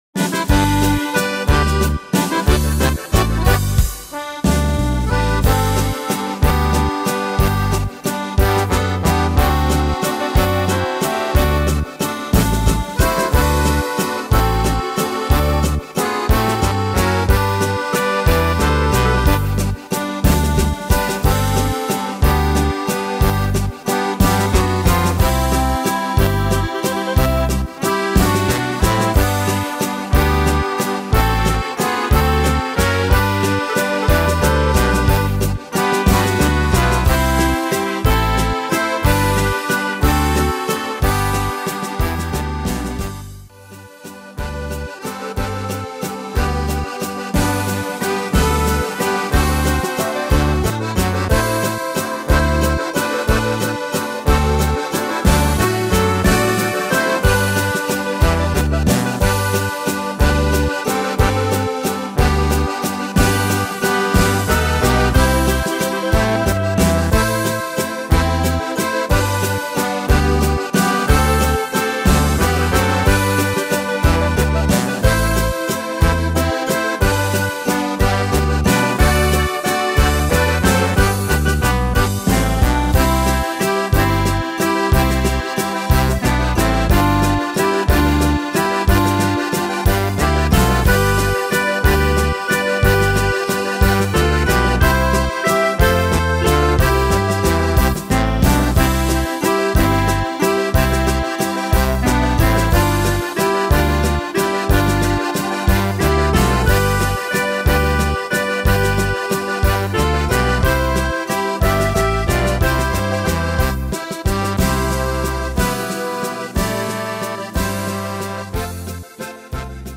Tempo: 182 / Tonart: G / C-Dur